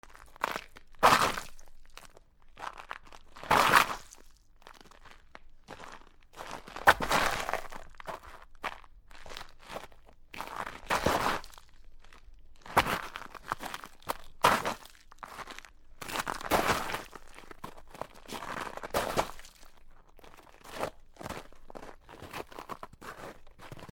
やや氷に近いような雪の上をすべる
/ M｜他分類 / L35 ｜雪・氷 /
MKH416